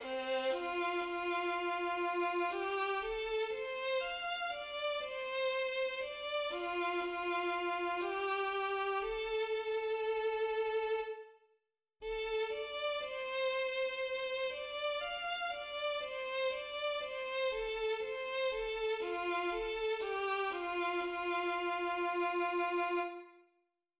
旋律はヨナ抜きの5音音階である。